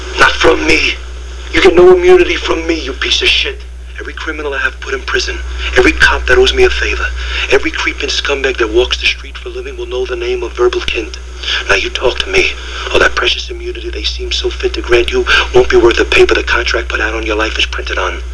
Convience - (Chazz From Usual Suspects, No immunity from me speech) 395KB